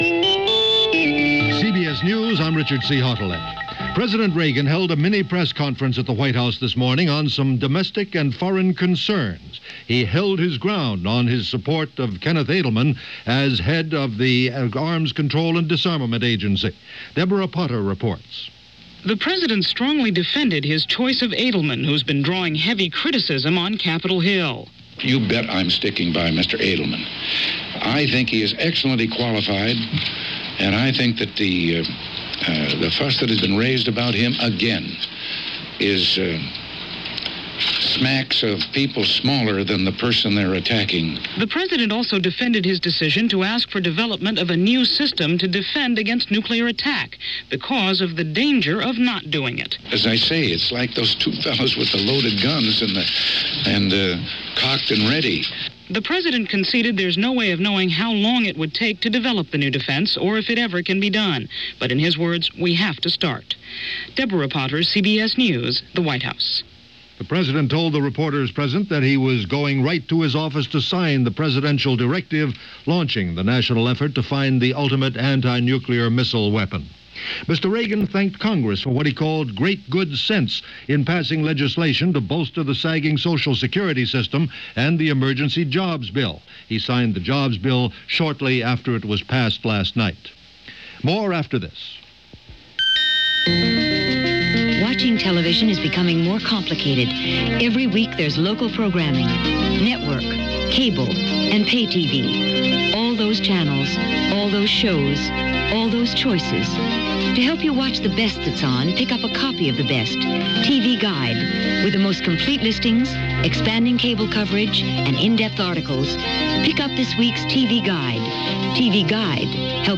CBS Radio News